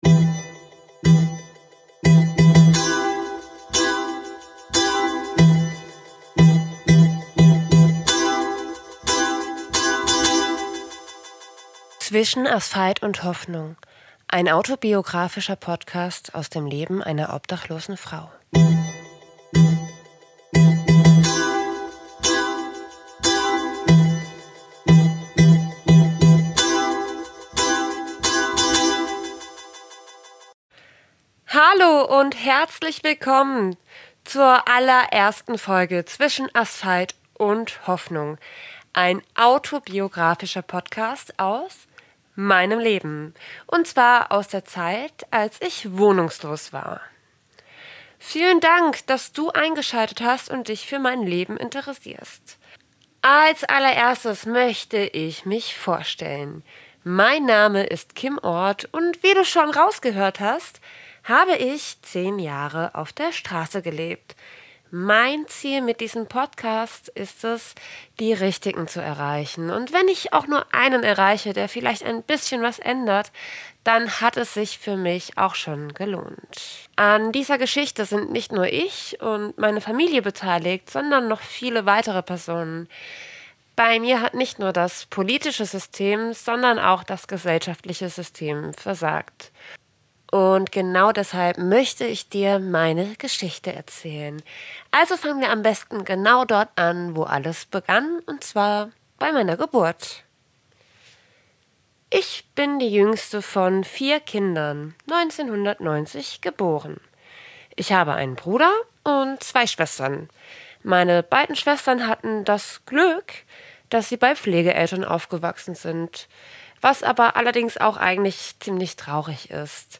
Geboren als Nutzvieh - Zwischen Asphalt und Hoffnung - Ein autobiographischer Podcast aus dem Leben einer obdachlosen Frau